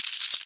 PixelPerfectionCE/assets/minecraft/sounds/mob/spider/say3.ogg at mc116